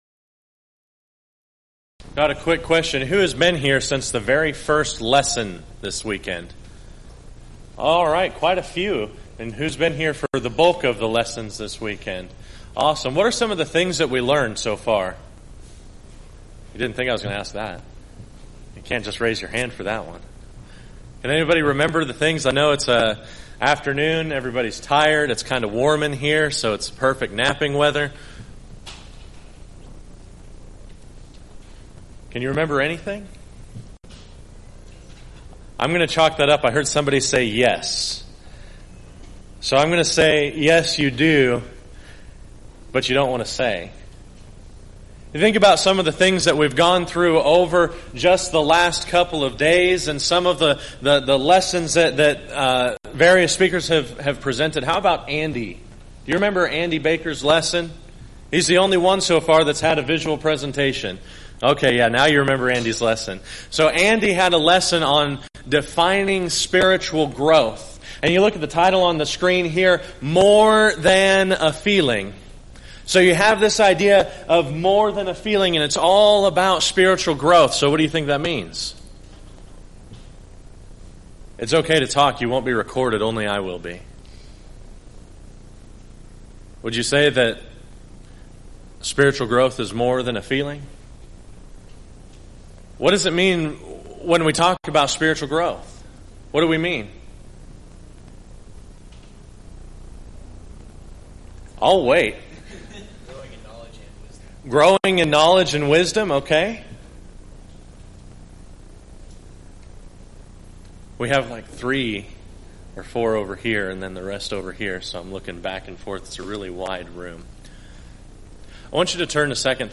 Event: 2nd Annual Arise Workshop
Youth Sessions